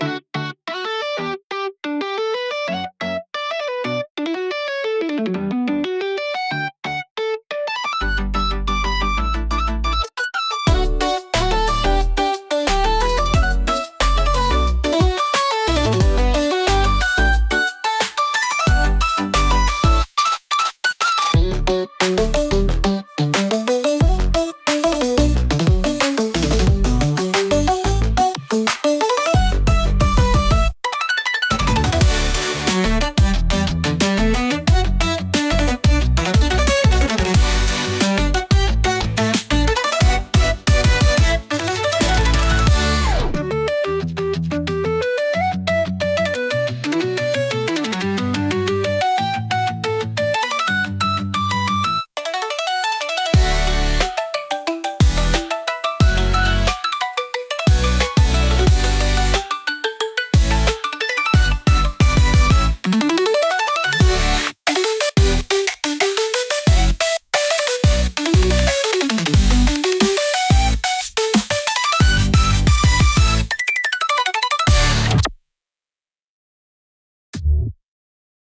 過ぎていく毎日を大切にするためのBGM